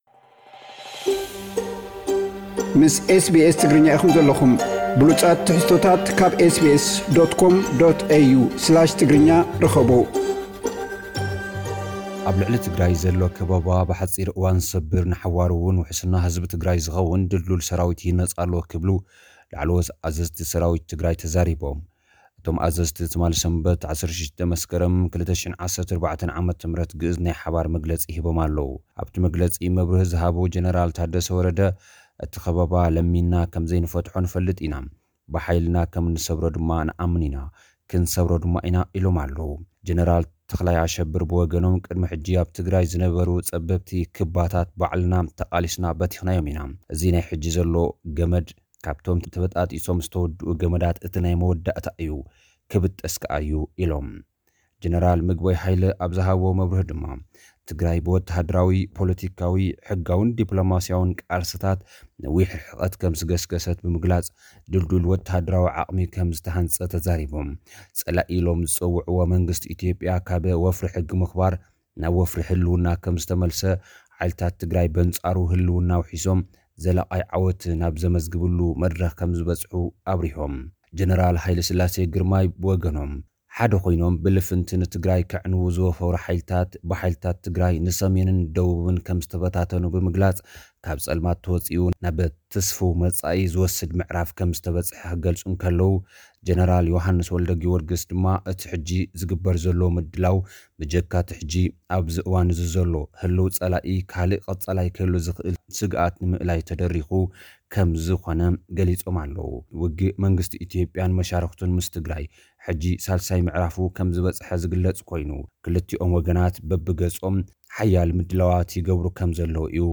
ጸብጻብ ዜናታት